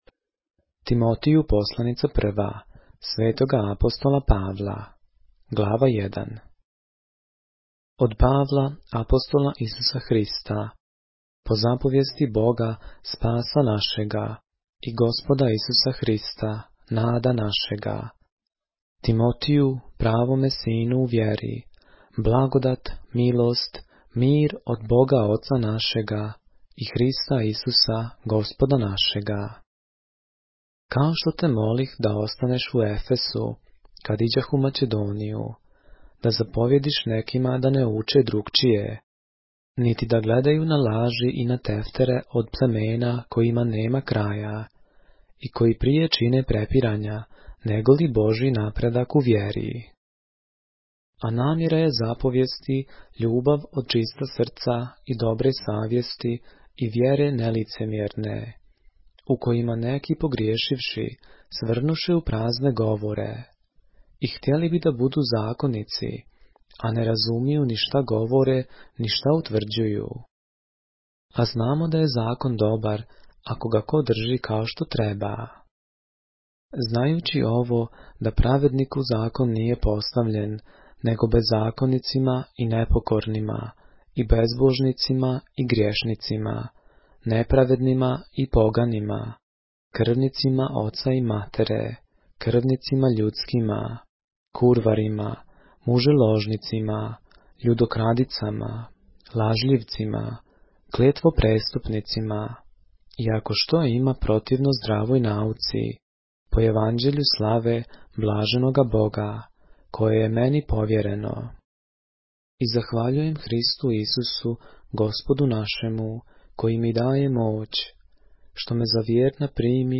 поглавље српске Библије - са аудио нарације - 1 Timothy, chapter 1 of the Holy Bible in the Serbian language